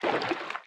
Sfx_creature_brinewing_swim_fast_05.ogg